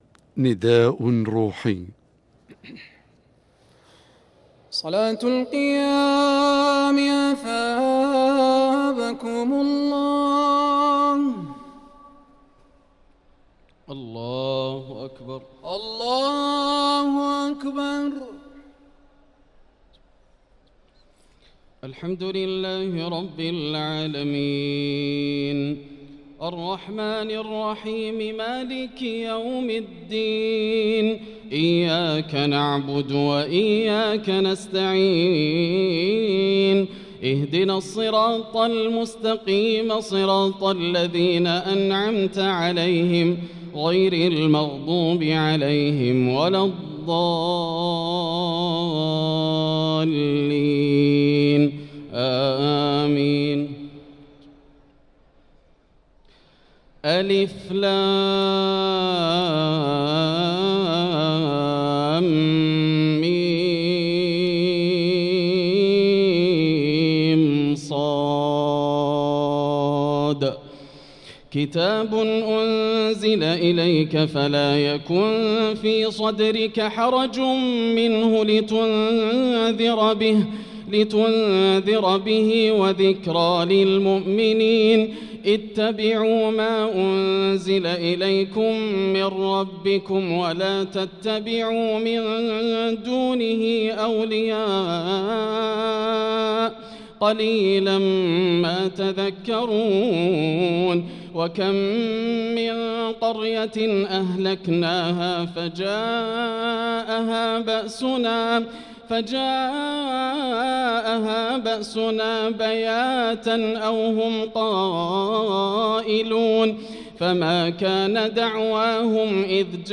صلاة التراويح ليلة 11 رمضان 1445 للقارئ ياسر الدوسري - الثلاث التسليمات الأولى صلاة التراويح